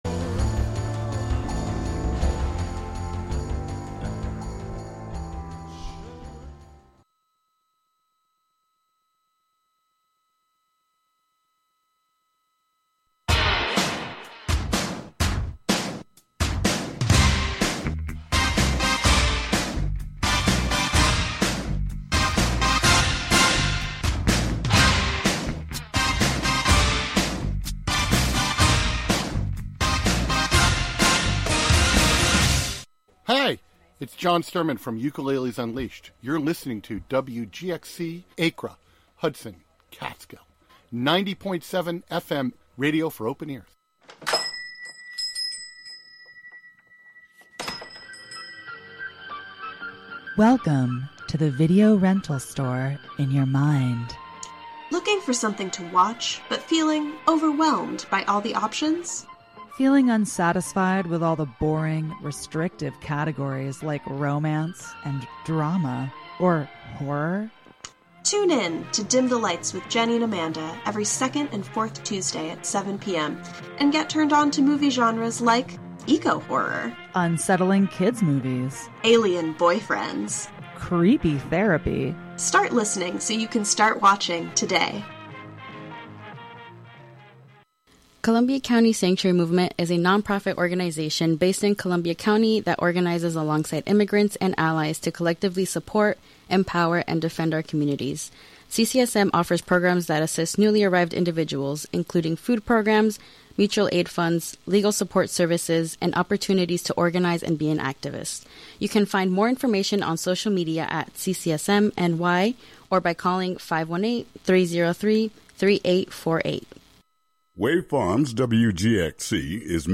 8pm Tonight, a variety of genres